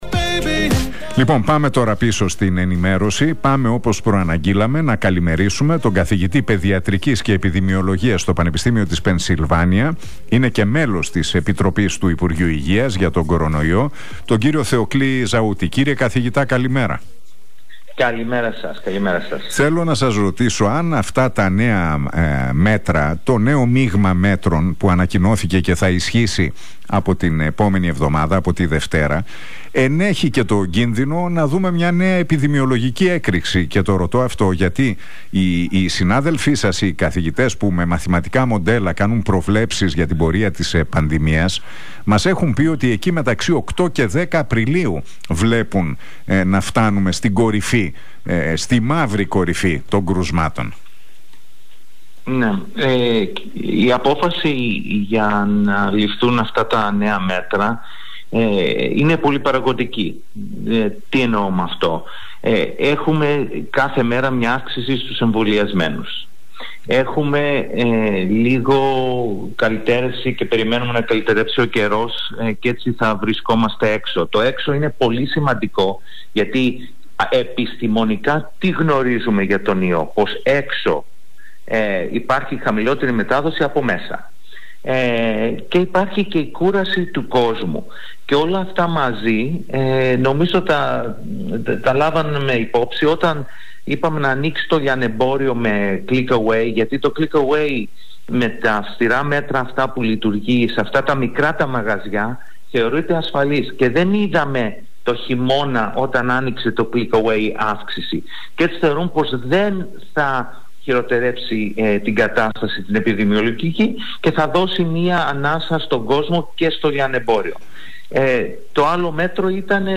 Στην εκπομπή του Νίκου Χατζηνικολάου στον Realfm 97,8 μίλησε ο Θεοκλής Ζαούτης, καθηγητής Παιδιατρικής και Επιδημιολογίας στο Πανεπιστήμιο της Πενσυλβάνια και μέλος της επιτροπής λοιμωξιολόγων.